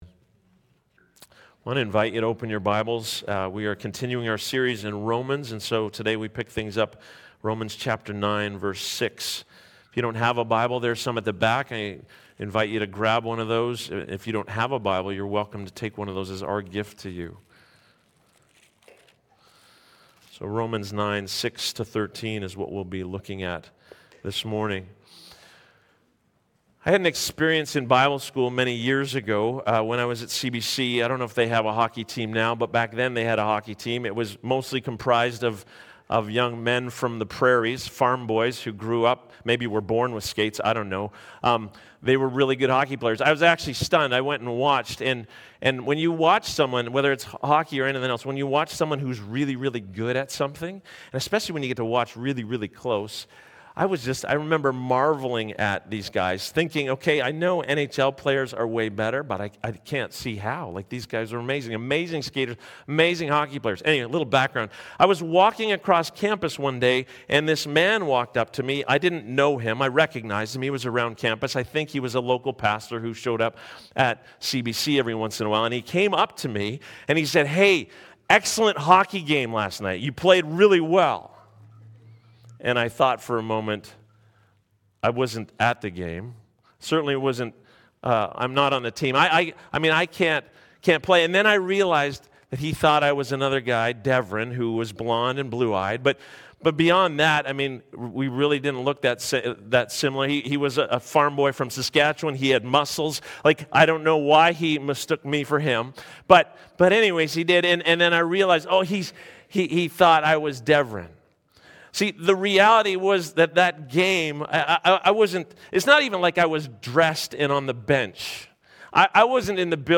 Sermons | Sunrise Community Church